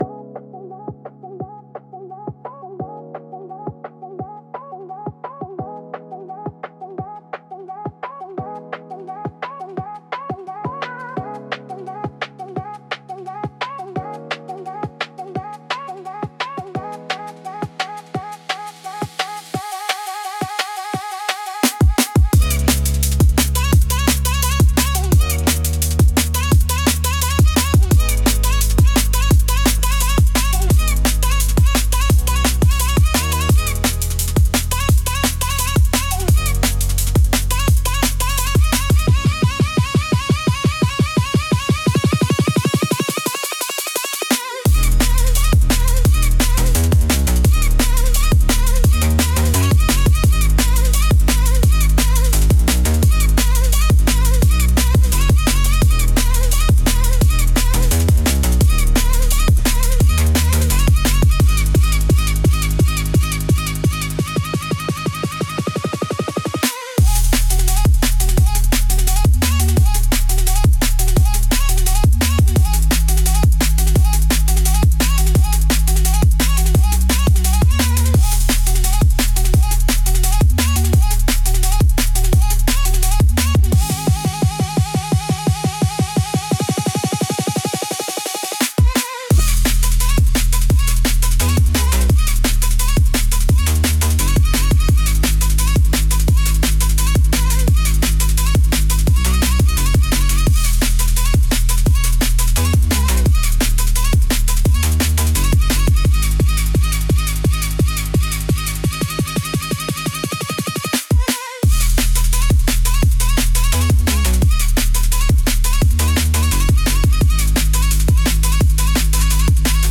テンポの速さと複雑なリズムで、聴く人の集中力と興奮を引き上げる効果があります。エッジの効いたダイナミックなジャンルです。